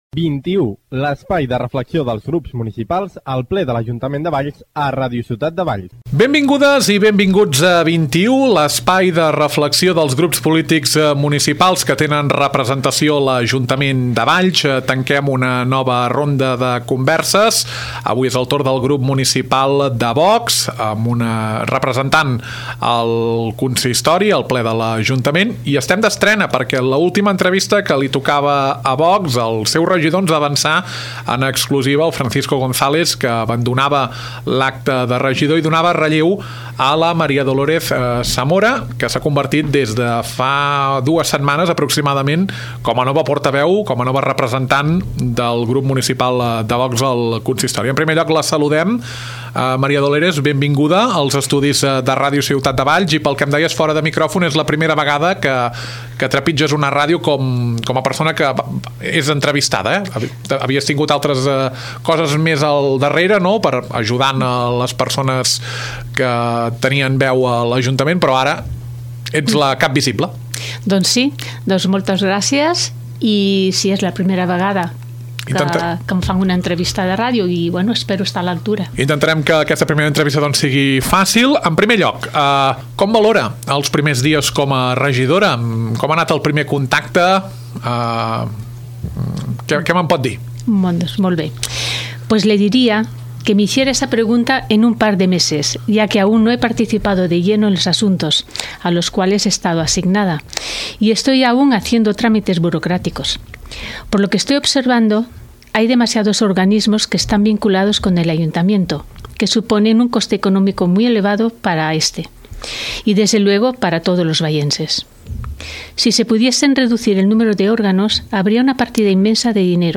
Nova temporada de 21, l’espai de reflexió dels grups municipals que tenen representació al ple de l’Ajuntament de Valls. Avui és el torn de Dolores Zamora, nova regidora del grup municipal de VOX.